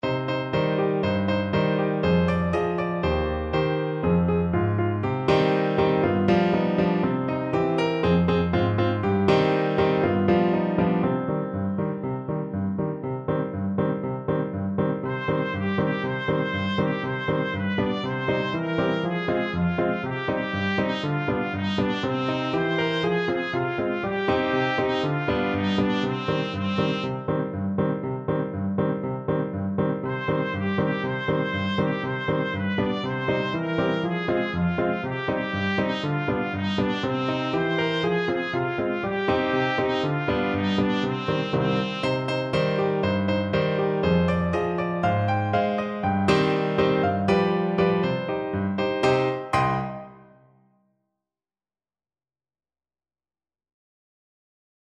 Trumpet
C minor (Sounding Pitch) D minor (Trumpet in Bb) (View more C minor Music for Trumpet )
2/4 (View more 2/4 Music)
Allegro (View more music marked Allegro)
Classical (View more Classical Trumpet Music)